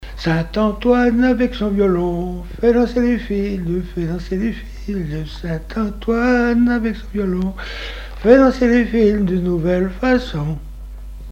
Chants brefs - A danser
danse : scottich trois pas
Répertoire instrumental à l'accordéon diatonique
Pièce musicale inédite